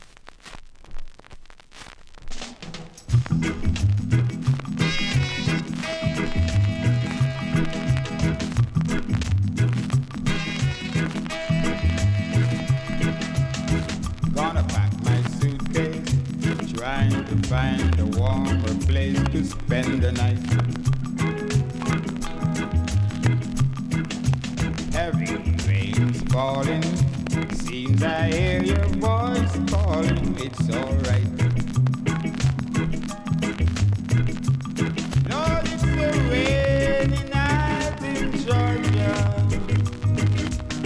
REGGAE 70'S